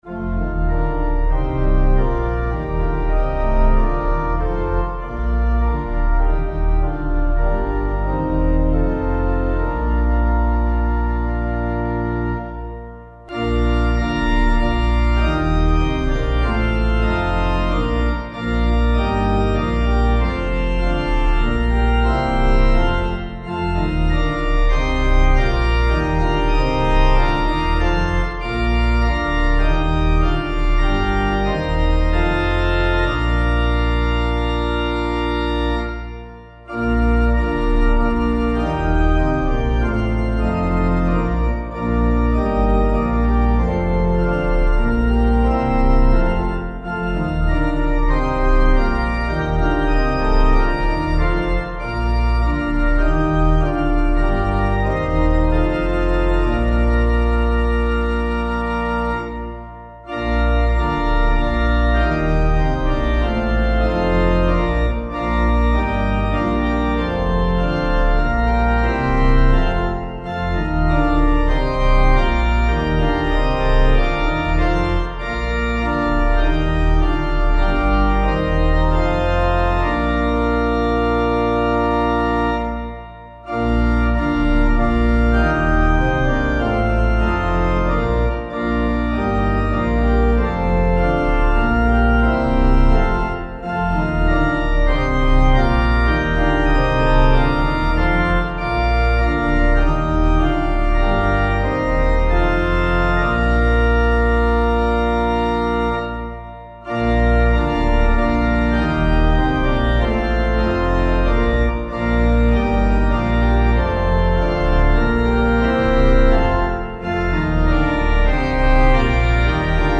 Meter:    88.88